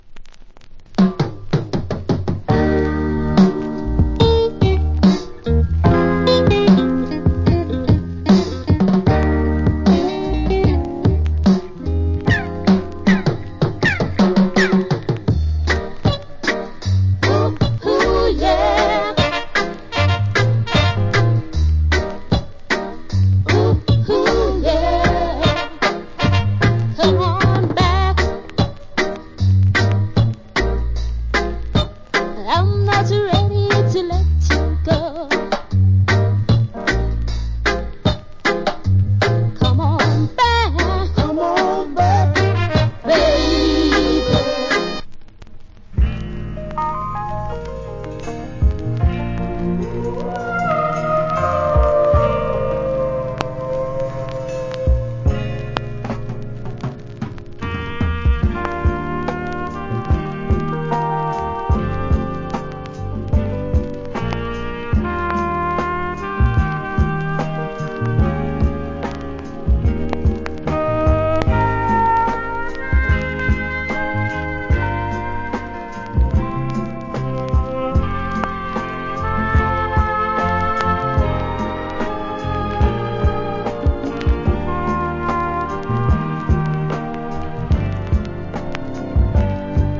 Cool Female Reggae Vocal.